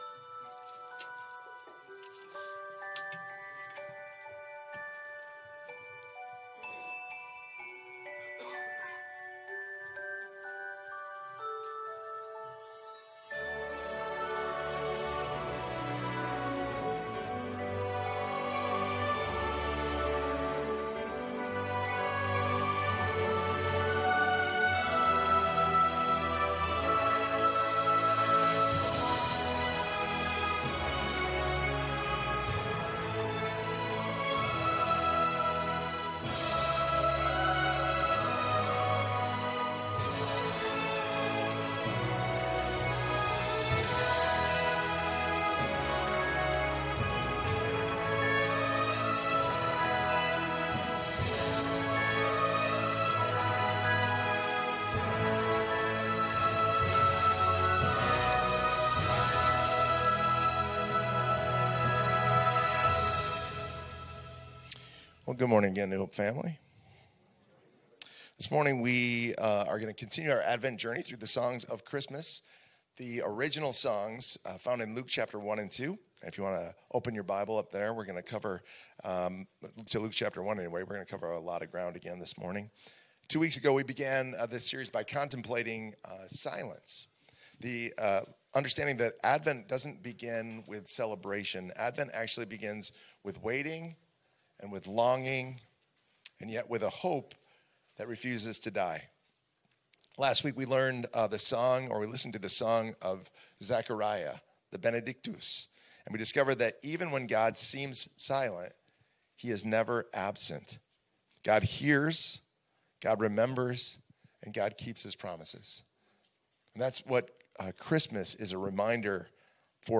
Sermons | New Hope Church